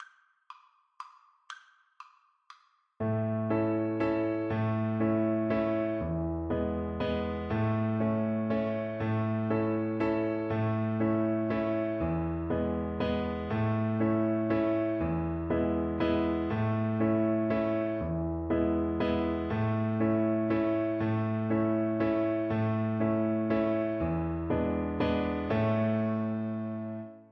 Violin
A major (Sounding Pitch) (View more A major Music for Violin )
3/4 (View more 3/4 Music)
Allegro = c.120 (View more music marked Allegro)
Traditional (View more Traditional Violin Music)